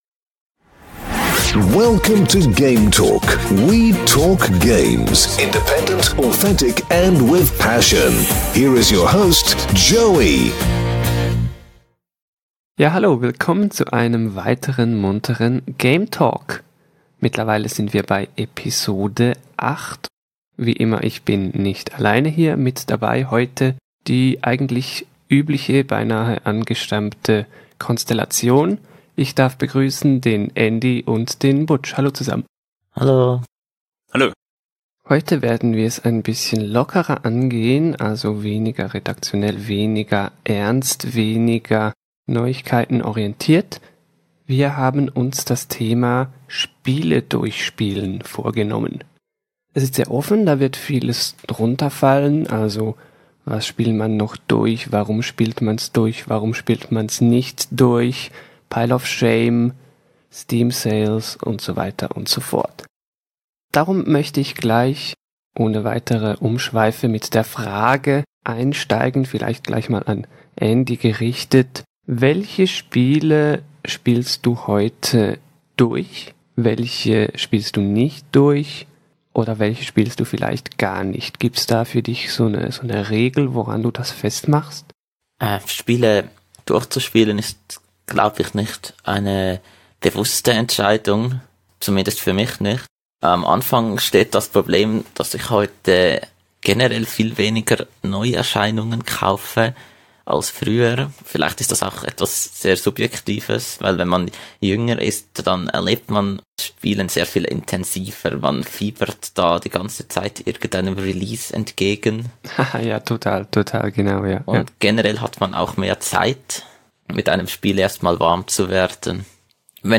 In der klassischen Konstellation versammelt, plaudern wird munter über das Durchspielen von Games: Welche Spiele zockt man heute noch durch?